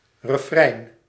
Ääntäminen
Synonyymit keerrijm Ääntäminen BE: IPA: /rəˈfræn/ NL: IPA: /rəˈfrɛi̯n/ Haettu sana löytyi näillä lähdekielillä: hollanti Käännös 1. refrão {m} Suku: n .